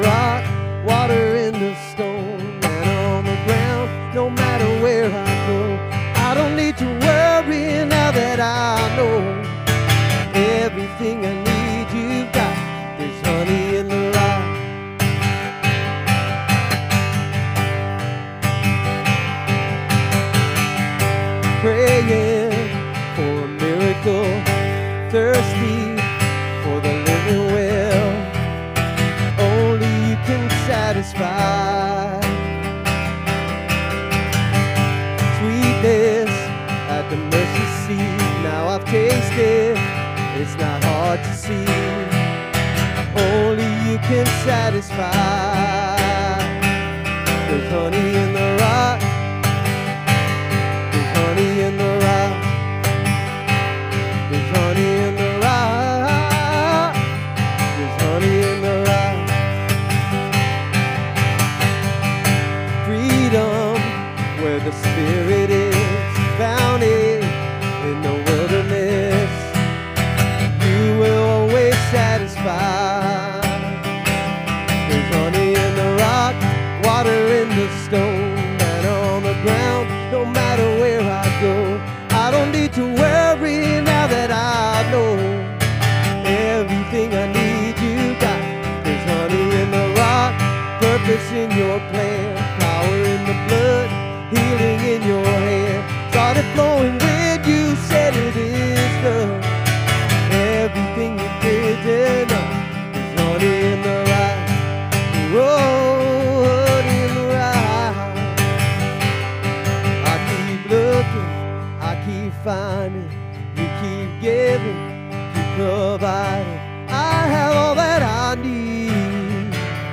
SERMON DESCRIPTION "How Much?" explores Jesus’ powerful lesson from the widow’s offering, revealing that true generosity is measured not by the amount given, but by the faith and sacrifice behind it.